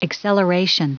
Prononciation du mot acceleration en anglais (fichier audio)
Prononciation du mot : acceleration